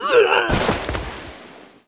男死亡倒地5zth070523.wav
通用动作/01人物/04人的声音/死亡/男死亡倒地5zth070523.wav
• 声道 單聲道 (1ch)